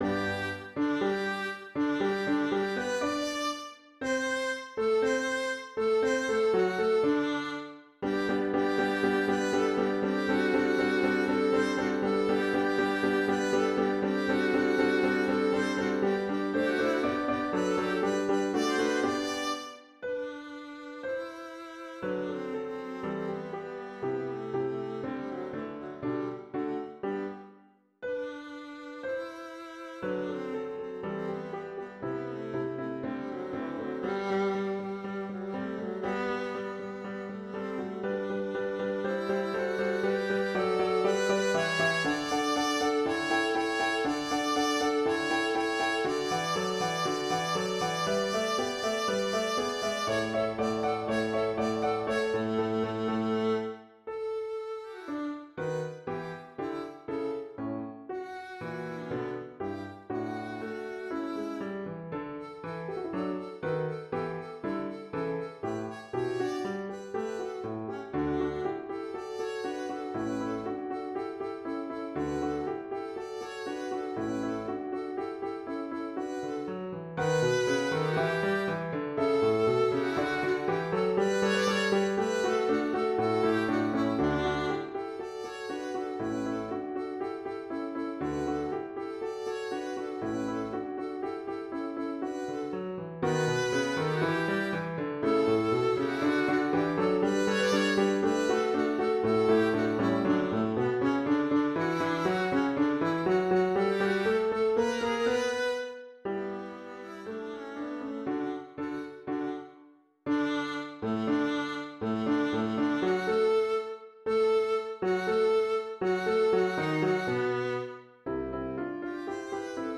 Viola and piano duet